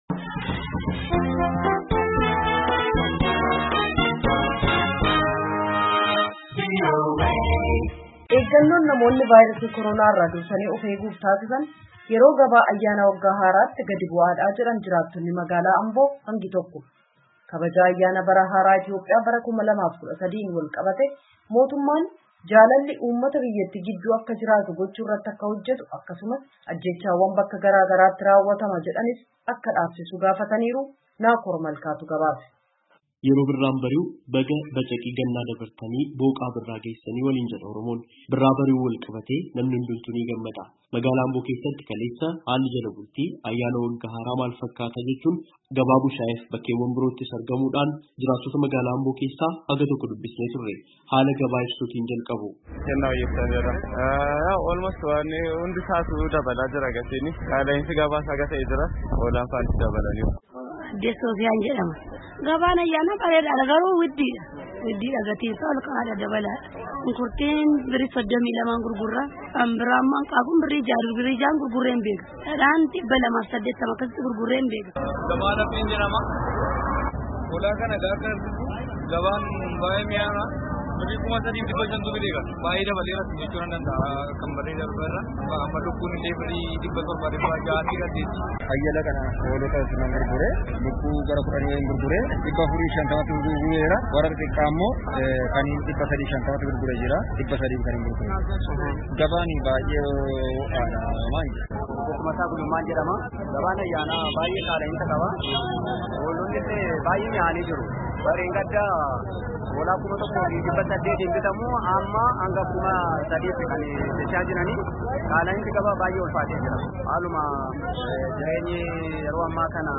Haalli Ayyaana Waggaa Haaraa Bara Kanaa Itti Ayyaaneffame Ka Dur Baratame Irraa Adda, Jedhu Namoonni Ambo Irraa VOAf Yaada Kennan
Yaada namootaa Ambo irraa walitt-qabame caqasaa.